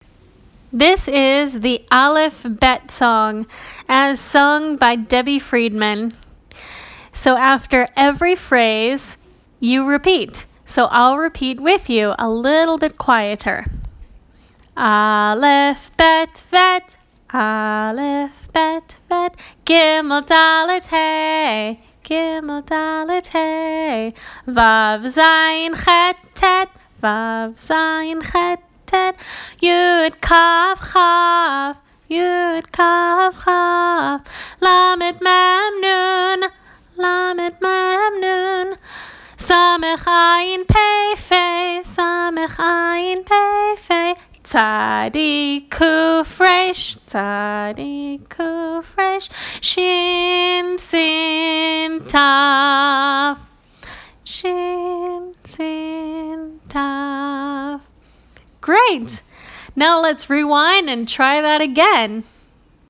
Jewish Music